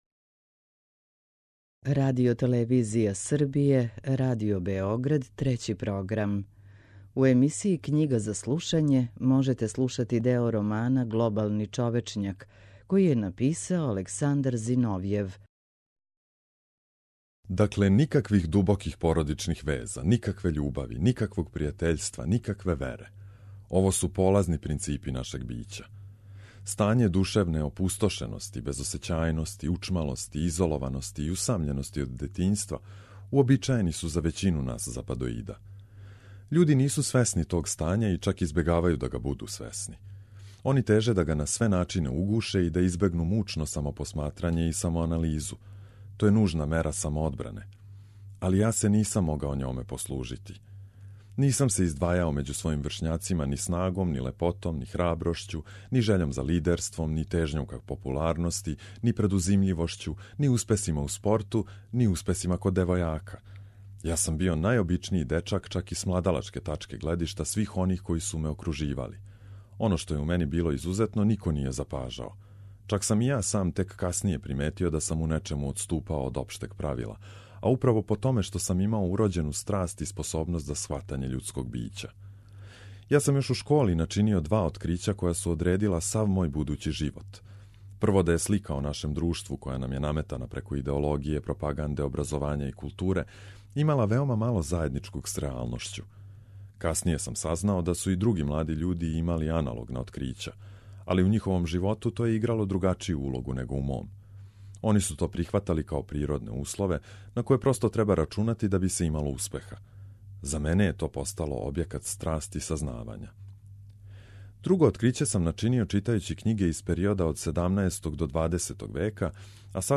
У емисији Књига за слушање можете пратити делове романа Александра Зиновјева „Глобални човечњак”.